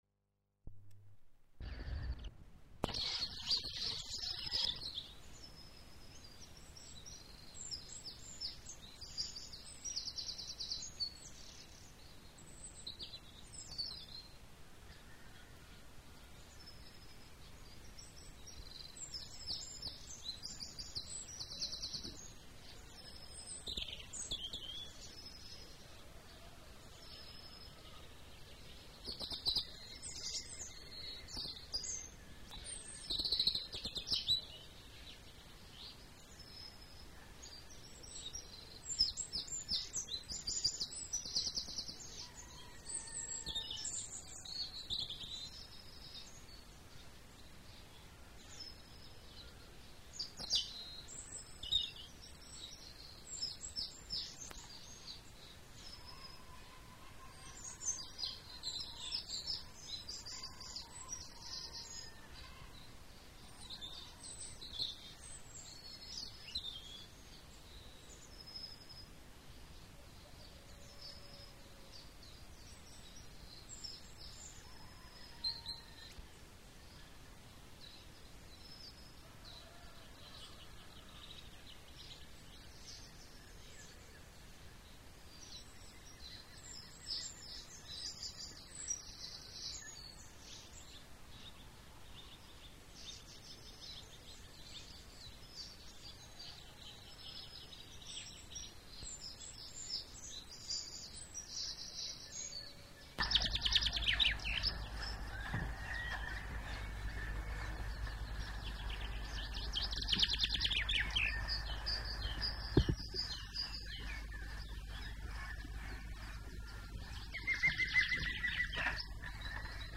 Enregistrement de chants d'oiseaux
Type de son : stéréo
Qualité technique : bon Résumé : Enregistrement de chants d'oiseaux, notamment le merle, le troglodyte, le picvert et le coucou à Roncevaux près de Sarramon.